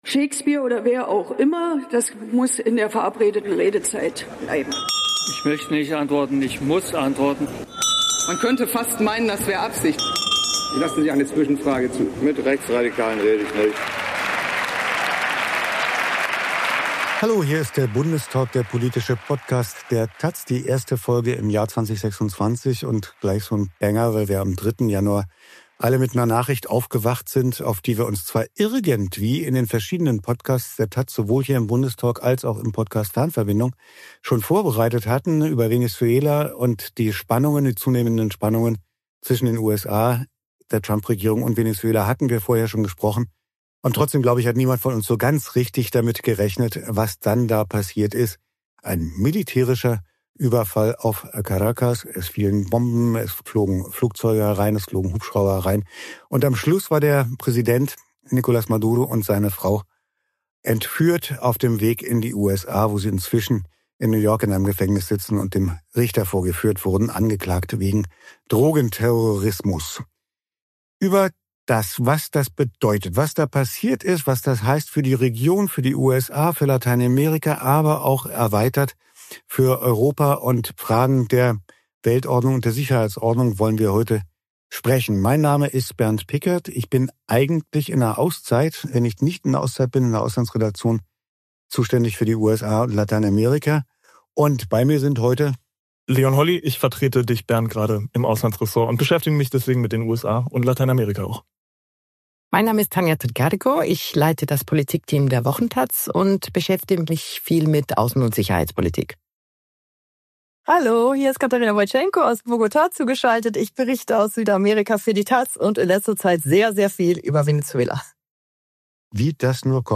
US-Militäraktion in Venezuela - Ist das Völkerrecht am Ende? ~ Bundestalk - Der Politik-Podcast der taz Podcast